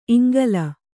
♪ iŋgala